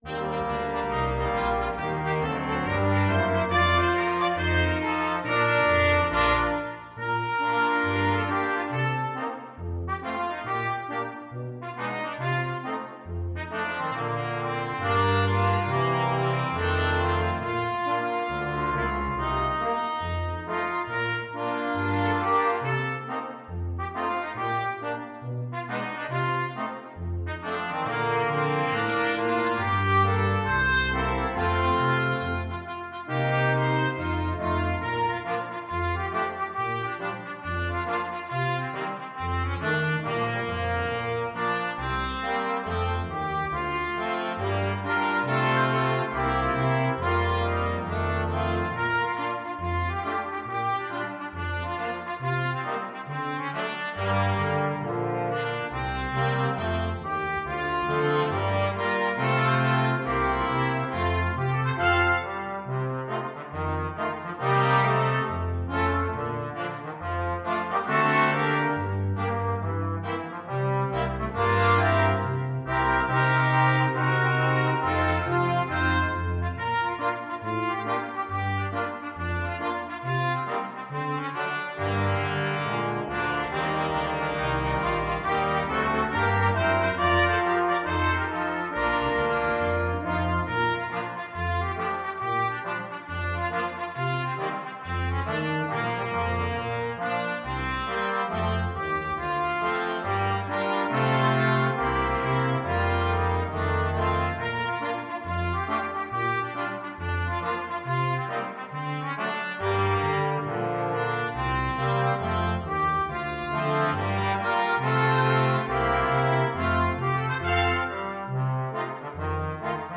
Trumpet 1Trumpet 2French HornTromboneTuba
4/4 (View more 4/4 Music)
With a swing =c.69
Pop (View more Pop Brass Quintet Music)